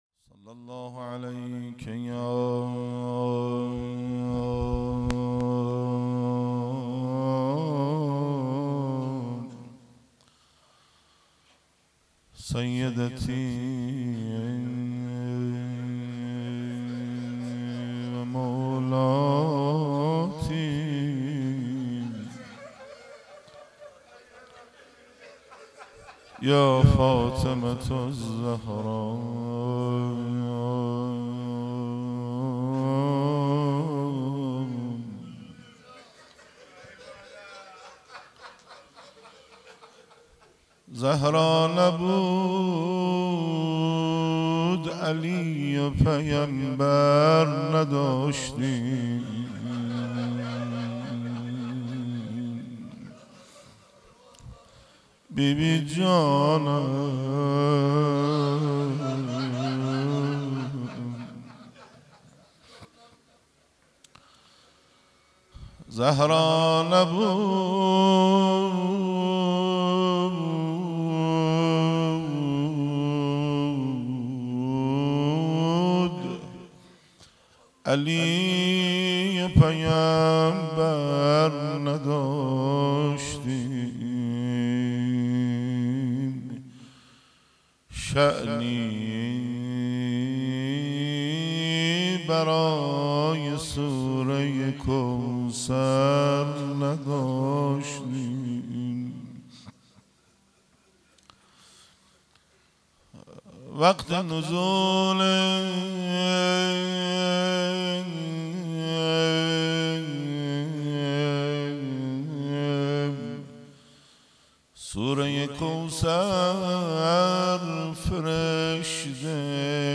روضه
ایام فاطمیه1393-روز اول.mp3